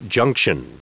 Prononciation du mot junction en anglais (fichier audio)
Prononciation du mot : junction